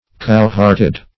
cowhearted - definition of cowhearted - synonyms, pronunciation, spelling from Free Dictionary
Search Result for " cowhearted" : The Collaborative International Dictionary of English v.0.48: Cowhearted \Cow"heart`ed\ (-h?rt`?d), a. Cowardly.